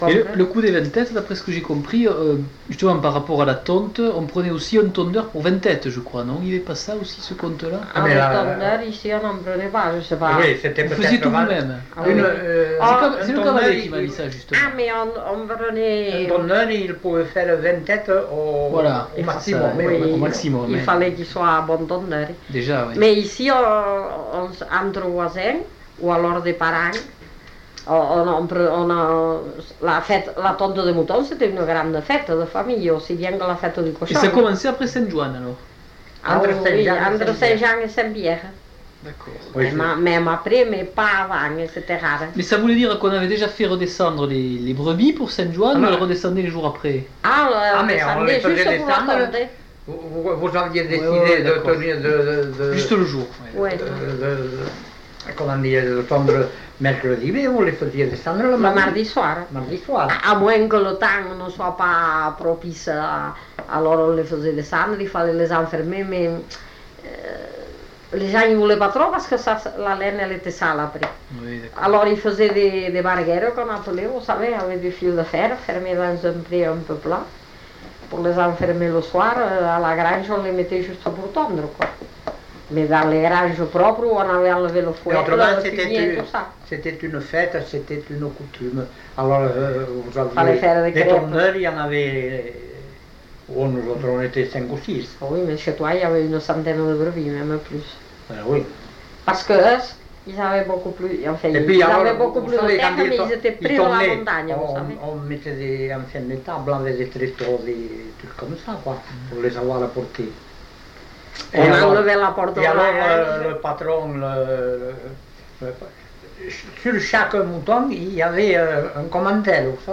Aire culturelle : Couserans
Lieu : Eylie (lieu-dit)
Genre : témoignage thématique